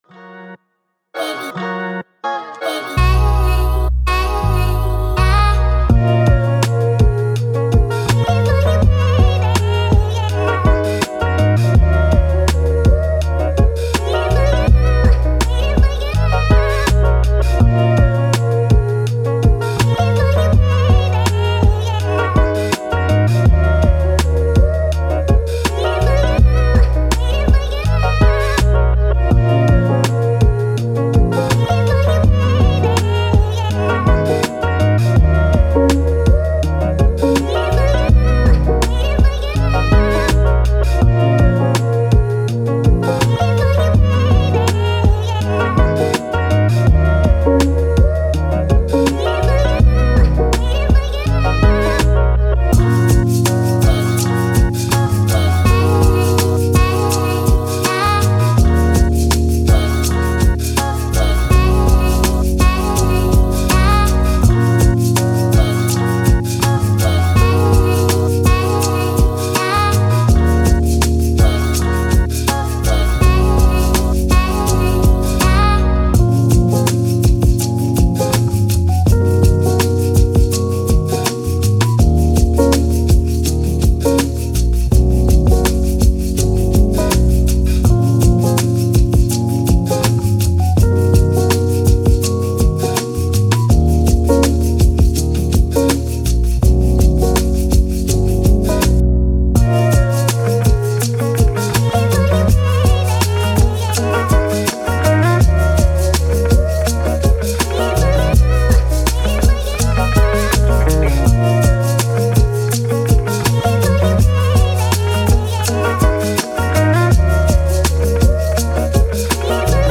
Hip Hop, Light, Positive, Uplifting